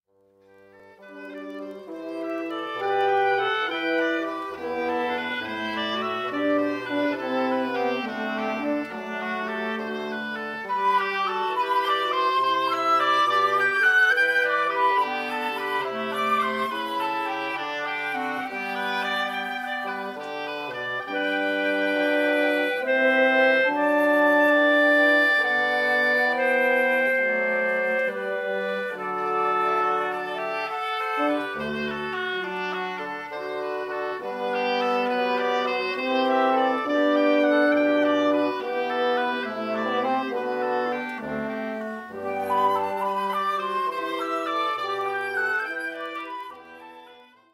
Popular prelude and ceremony music for weddings